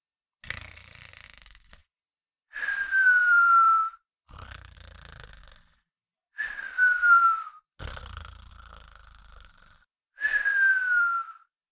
Cartoon Little Monster, Voice, Snore, Snoring 2 Sound Effect Download | Gfx Sounds
Cartoon-little-monster-voice-snore-snoring-2.mp3